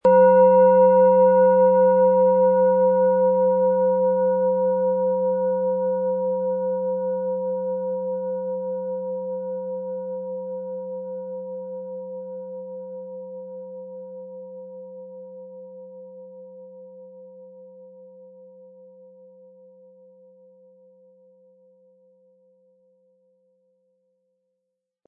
Ihre antike Klangschale – Klarheit und Wachheit im Klang des Biorhythmus Geist
Mit ihrem klaren, zentrierenden Klang entfaltet die Schale eine sanfte, fokussierende Präsenz – besonders im Bereich von Brust, Kopf und Stirn.
Die Schwingungen sind kraftvoll und fein zugleich, spürbar am Boden der Schale und bis in die Hände hinein.
Im Sound-Player - Jetzt reinhören können Sie den Original-Ton genau dieser Schale anhören.
PlanetentonBiorythmus Geist
MaterialBronze